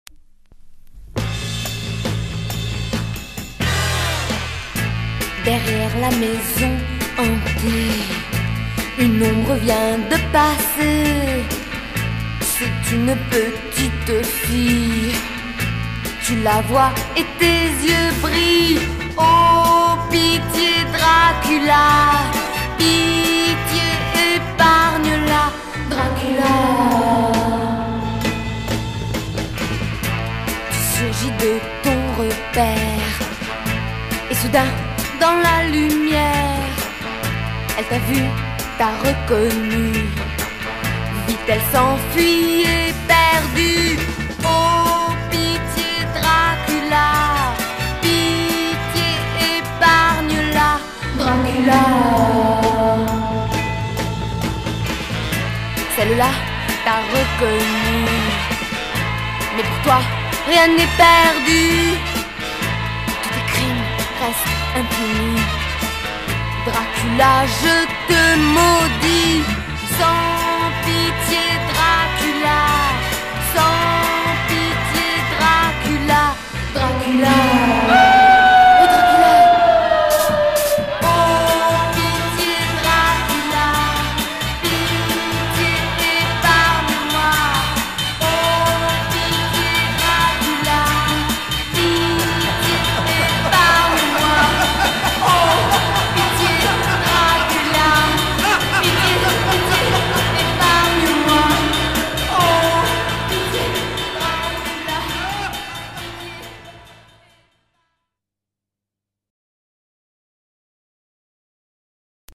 au son particulièrement sixties